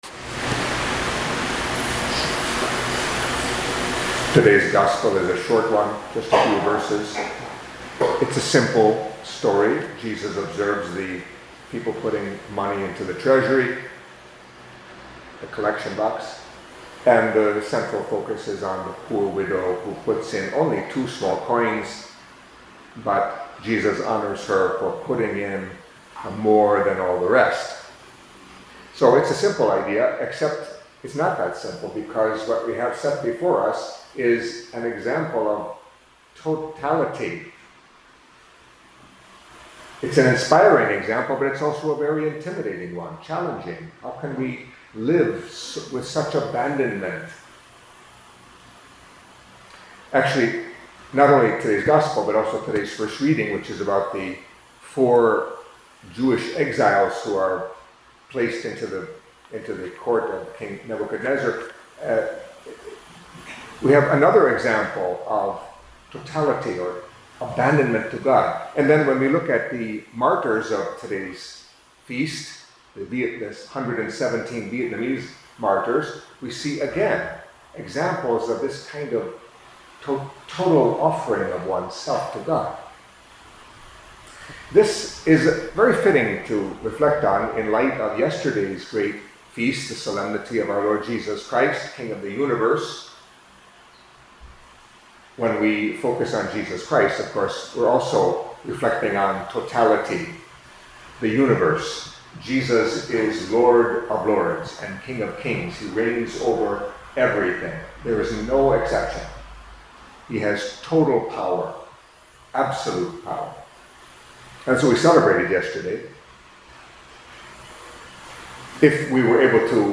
Catholic Mass homily for Monday of the Thirty-Fourth Week in Ordinary Time